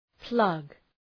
{plʌg}